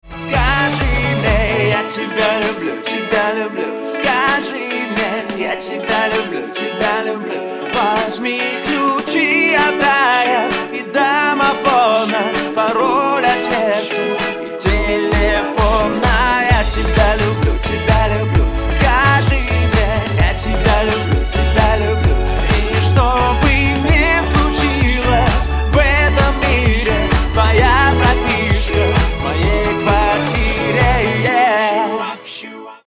поп
мужской вокал
романтичные